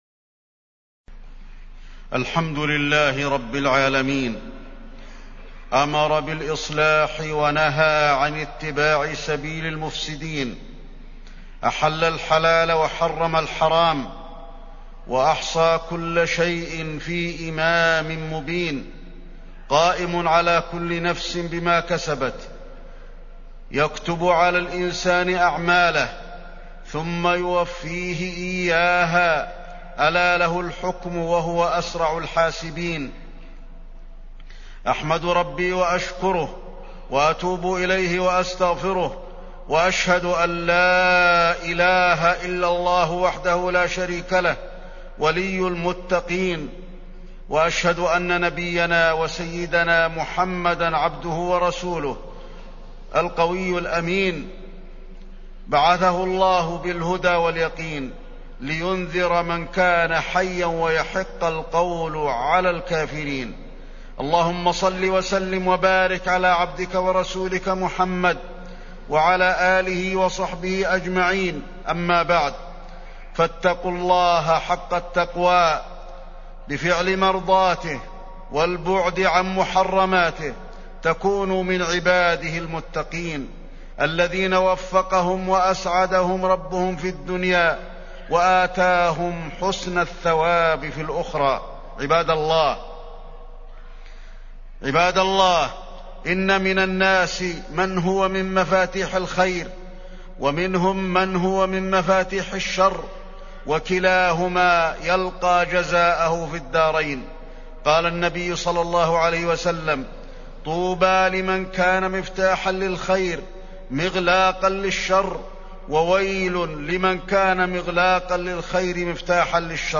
تاريخ النشر ١٢ صفر ١٤٢٨ هـ المكان: المسجد النبوي الشيخ: فضيلة الشيخ د. علي بن عبدالرحمن الحذيفي فضيلة الشيخ د. علي بن عبدالرحمن الحذيفي الأحداث الإرهابية في المدينة